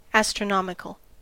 ÄäntäminenUS
• IPA: /ˌæstrəˈnɑːmɪkəl/